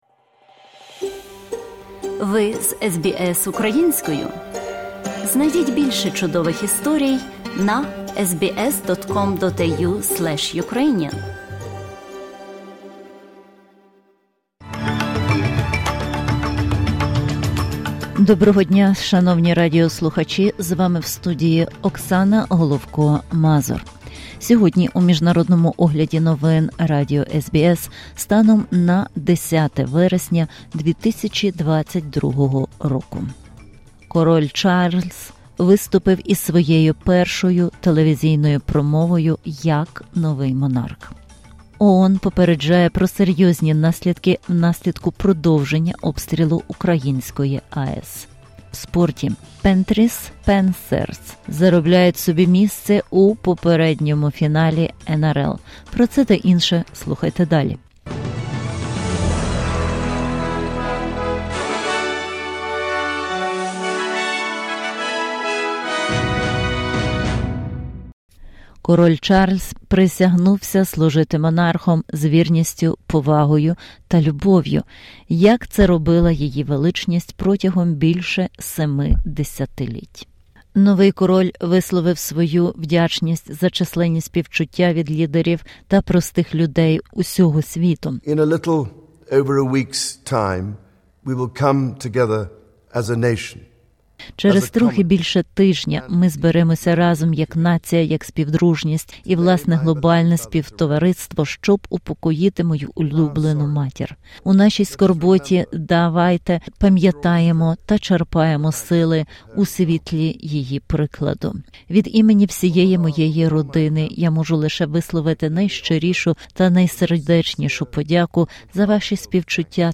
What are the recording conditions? SBS Ukrainian, 3 pm FM, TV Ch. 38 and 302, every Thursday Source: SBS